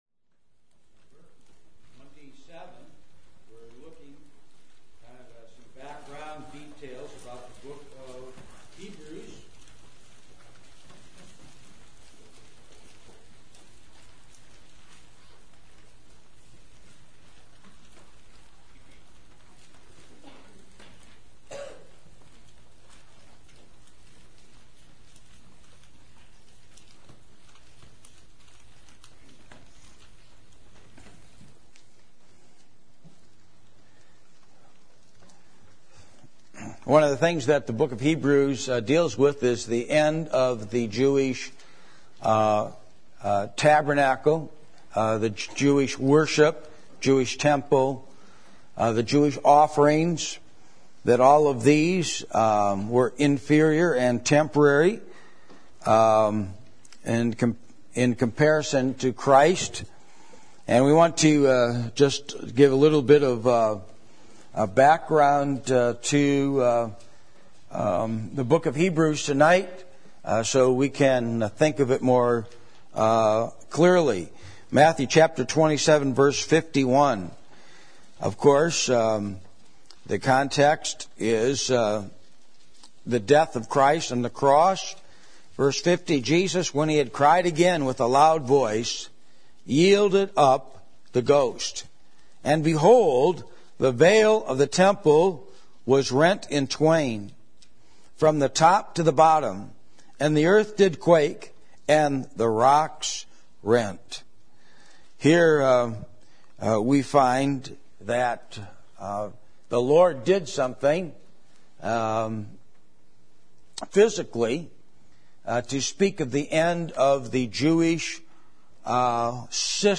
Service Type: Special event